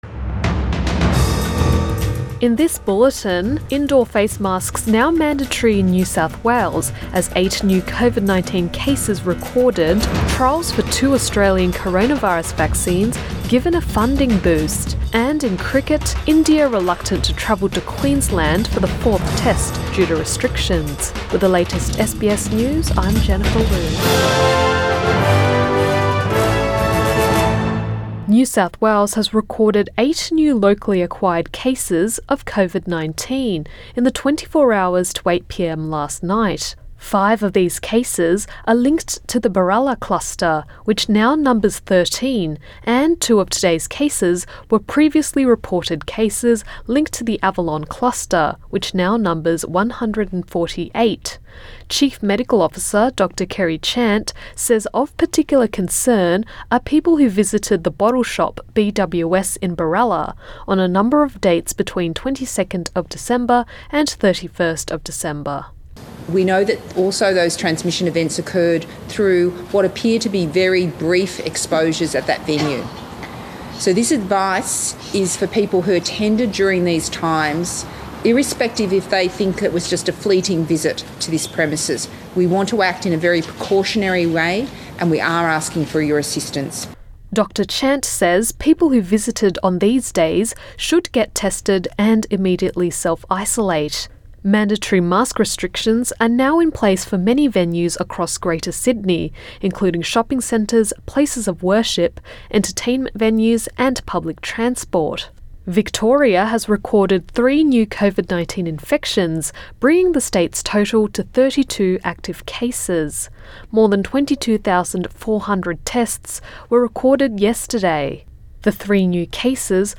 PM bulletin 3 January 2021